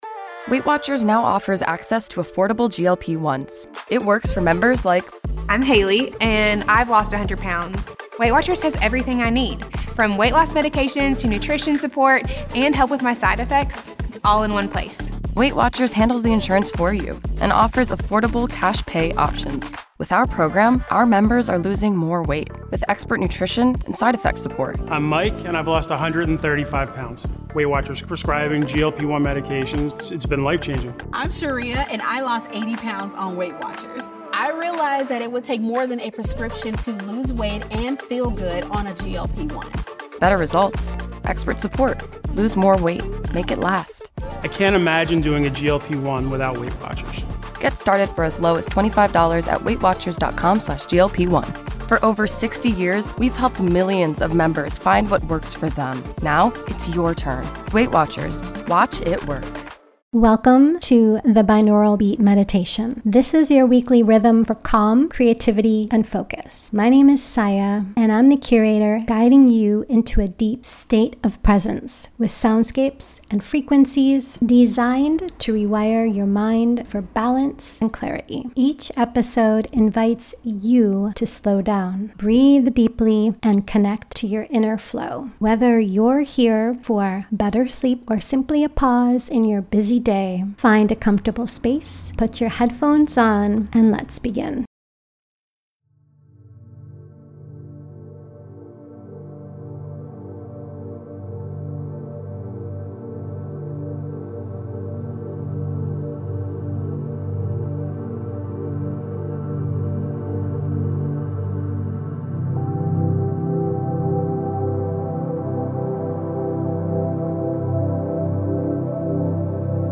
14hz - Beta Beats for Clear Thinking & Steady Concentration ~ Binaural Beats Meditation for Sleep Podcast